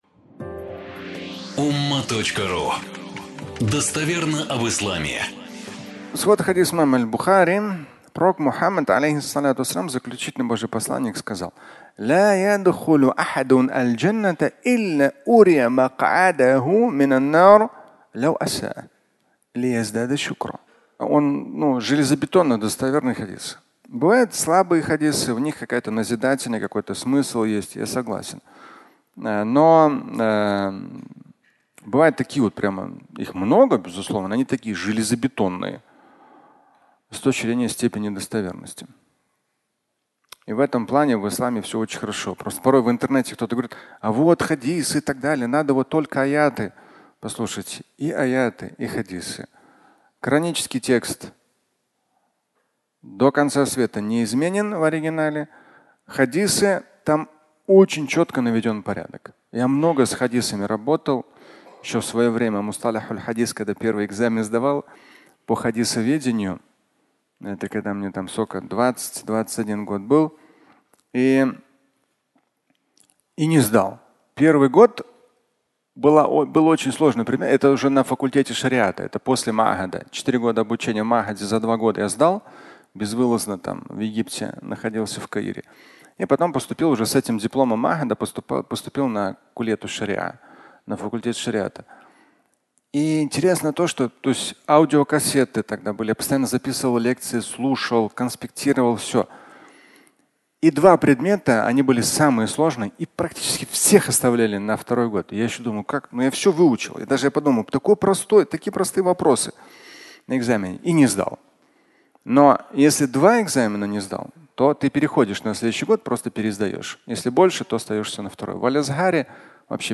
Место в Аду (аудиолекция)